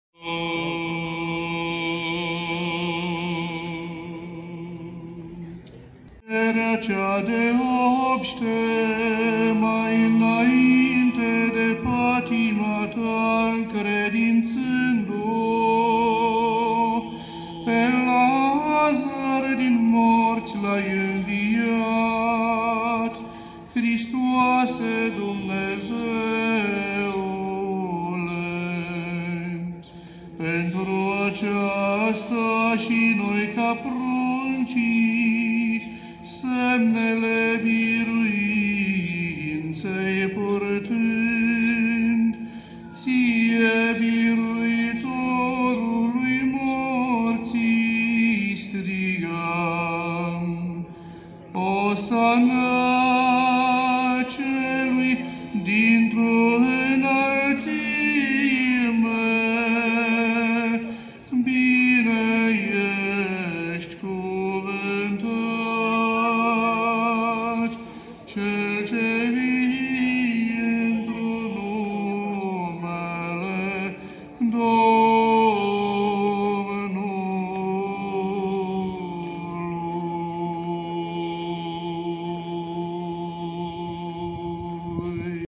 În mireasmă de salcie, mir și mare, zeci de constănțeni au participat la slujba de Florii, oficiată în aer liber, pe platoul din fața Catedralei Arhiepiscopale „Sfinții Apostoli Petru și Pavel” din Constanța.
slujba.mp3